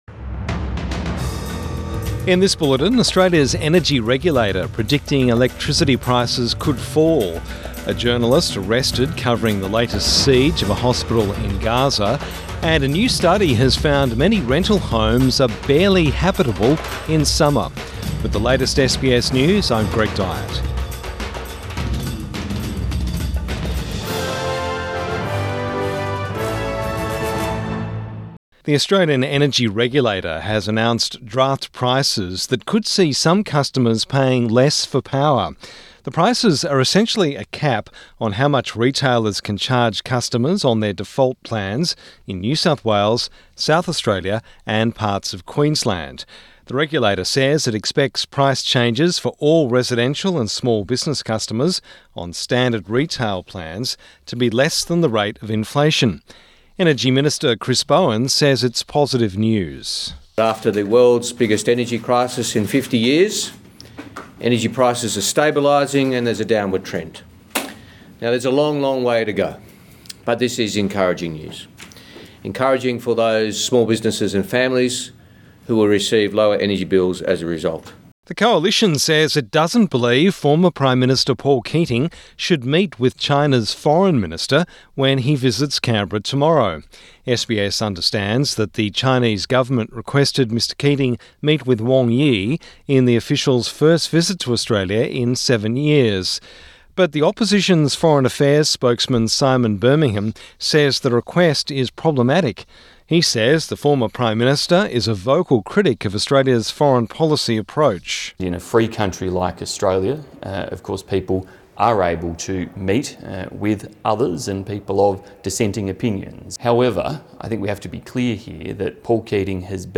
Midday News Bulletin 19 March 2024